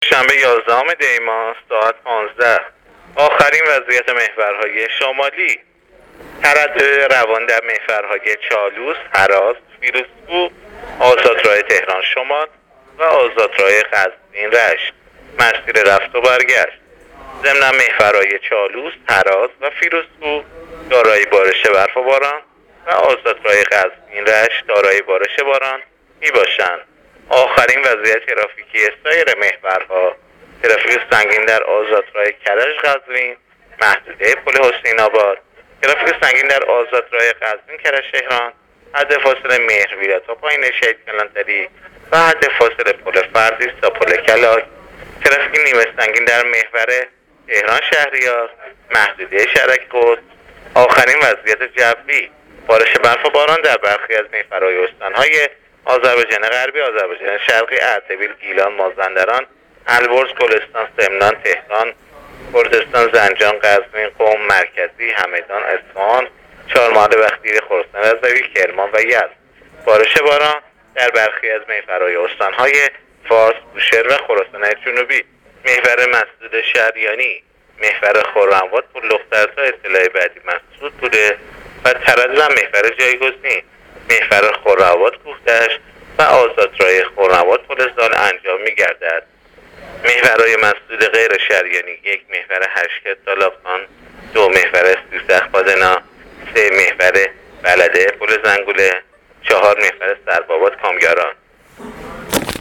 گزارش رادیو اینترنتی از آخرین وضعیت ترافیکی جاده‌ها تا ساعت ۱۵ یازدهم دی؛